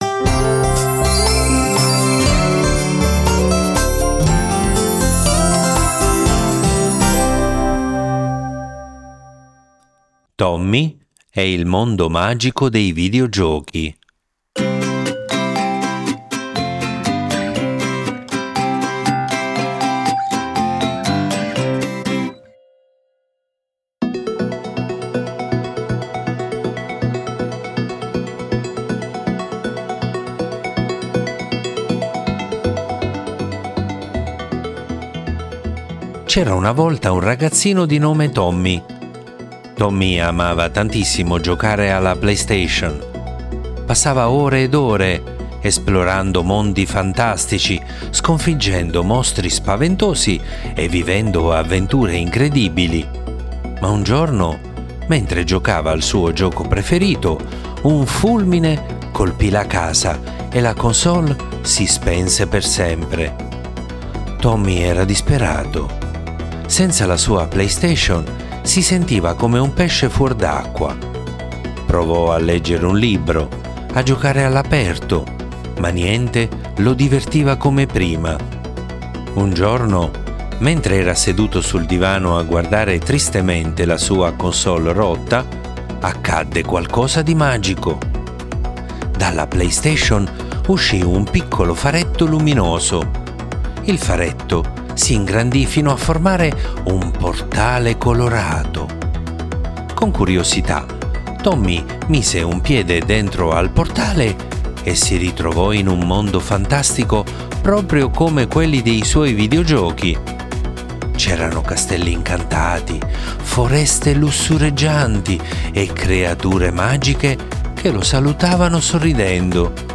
Le favole della buonanotte